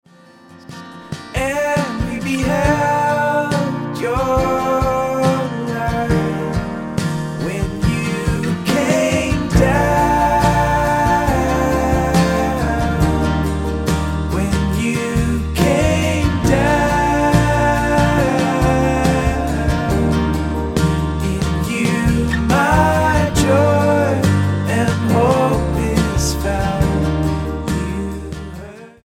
STYLE: Ambient/Meditational
is in a suitably sombre mood
the formula of guitar and keys continues